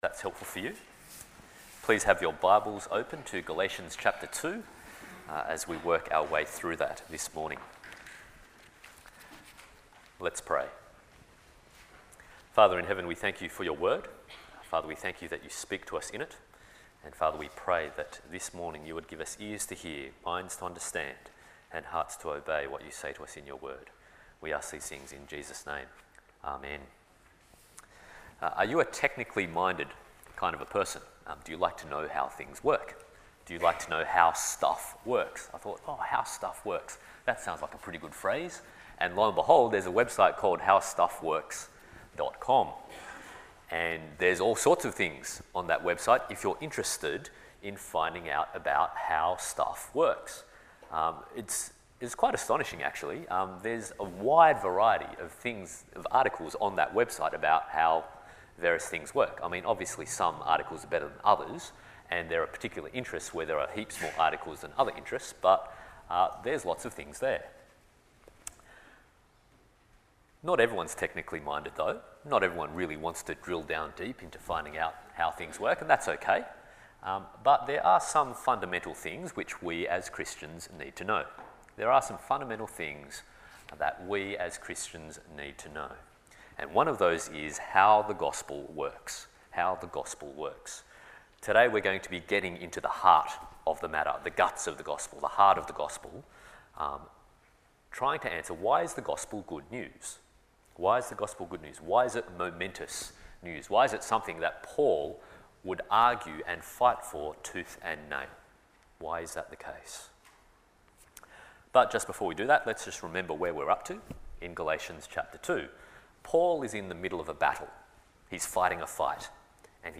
Galatians Passage: Psalm 143:1-12, Galatians 2:15-21 Service Type: Sunday Morning « Walking In Truth